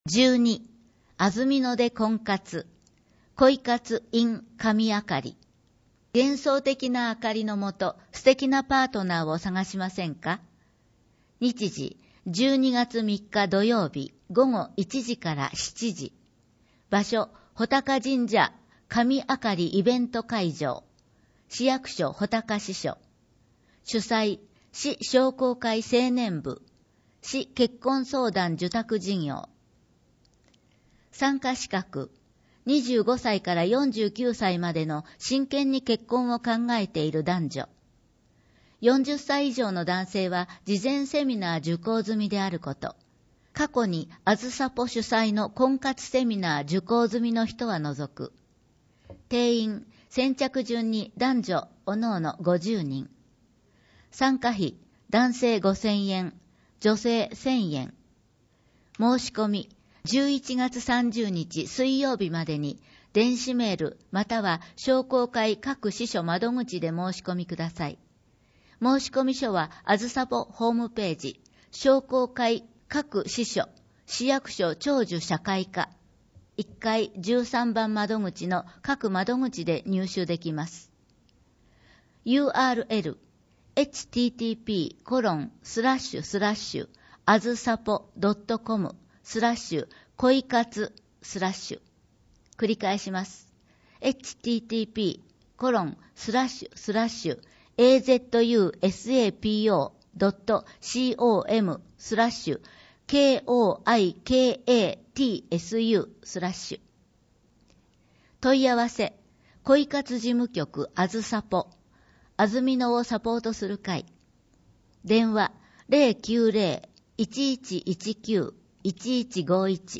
広報あづみの朗読版239号（平成28年11月16日発行)
「広報あづみの」を音声でご利用いただけます。